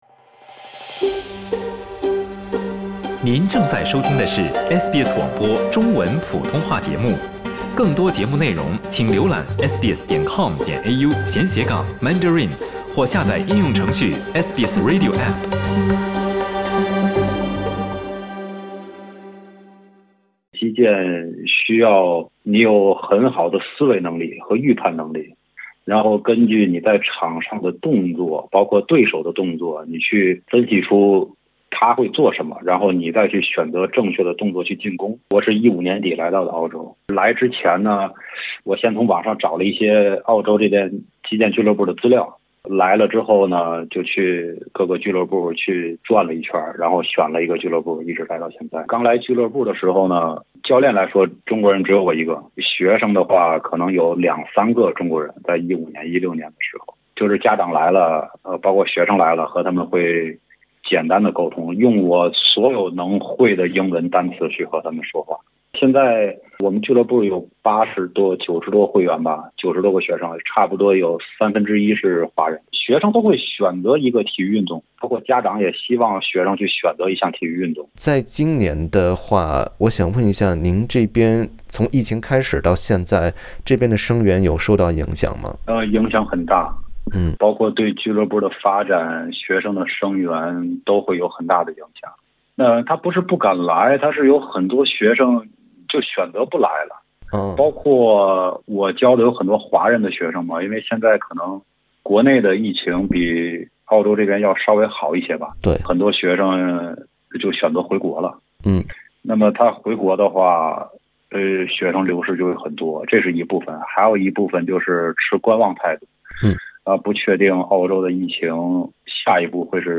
收听完整的采访